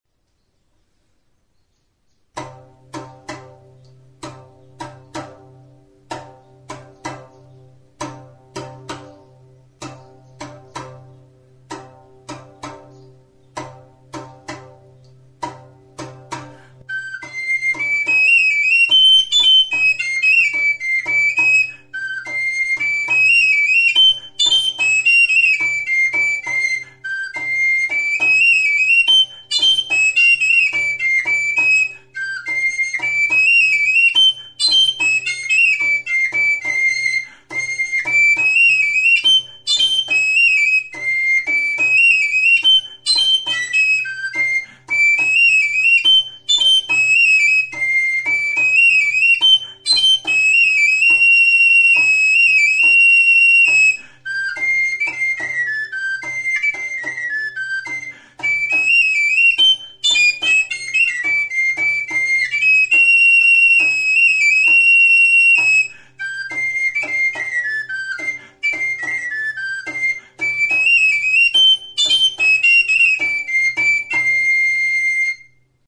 Enregistré avec cet instrument de musique.
DANBURIA; TTUNTTUNA; SOINUA; SALTERIOA
Cordes -> Battues
Sei sokazko zuberotar danburia da.